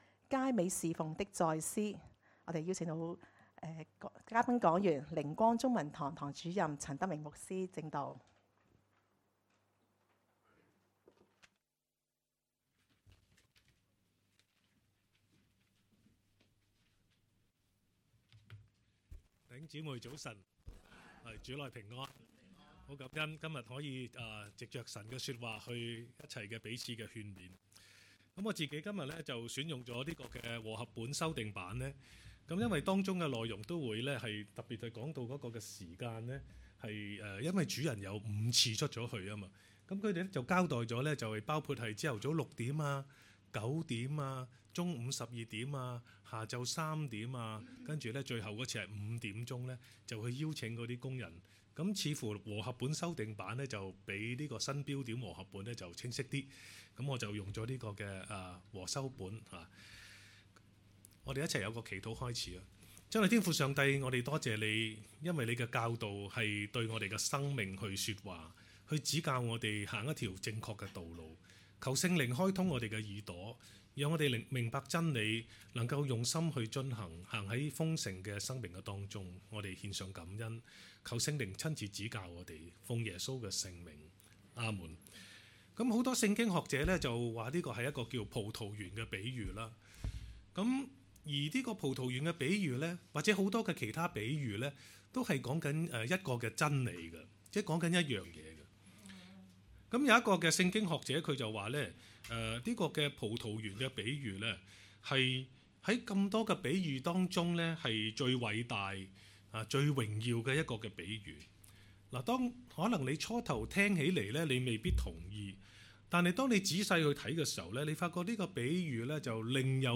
講道 ：佳美事奉的再思